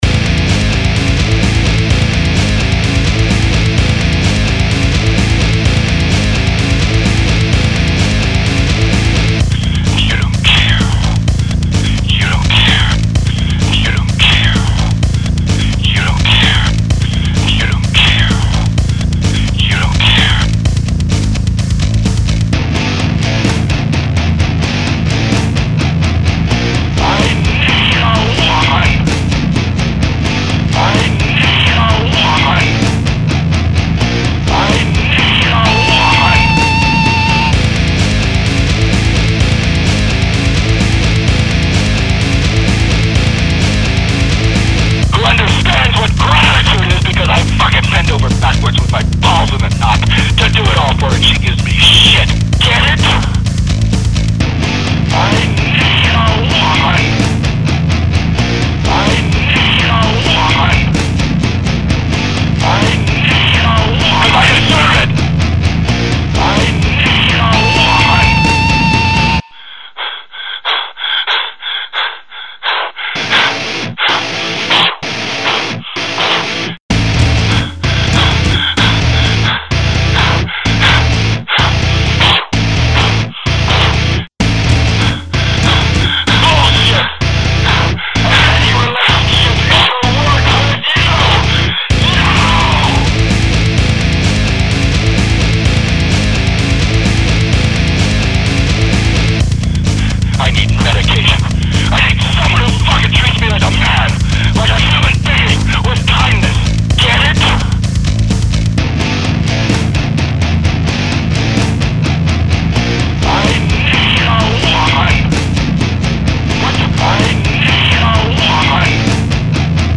This one is a metal song featuring a guest vocal